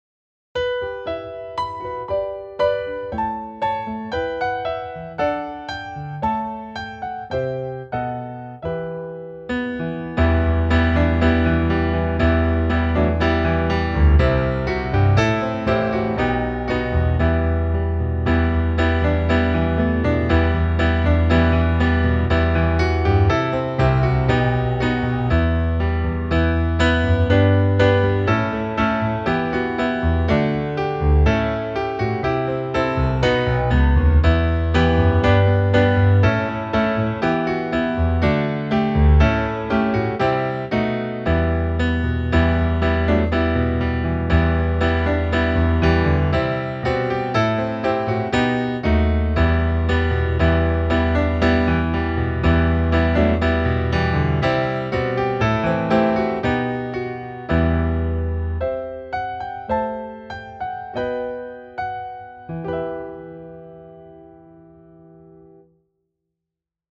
komm-heilger-geist-klavier.mp3